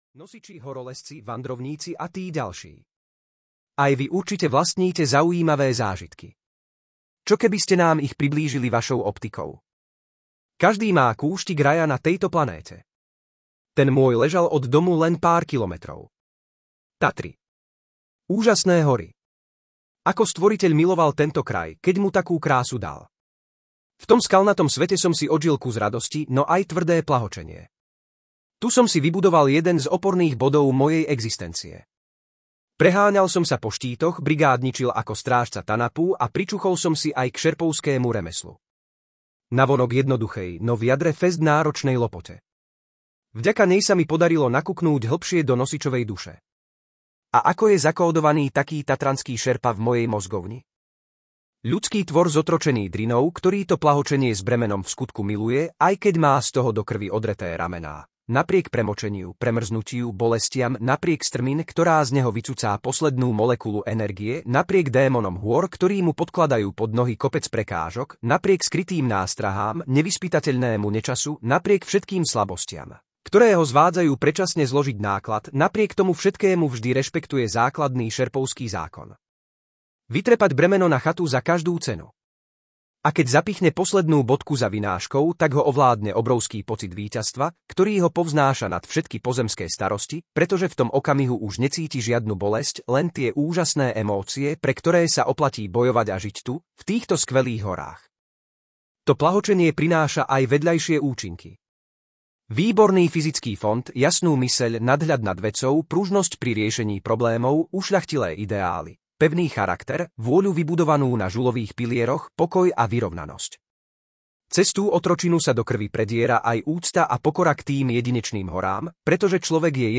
Tatry, nosiči a iné živly audiokniha
Ukázka z knihy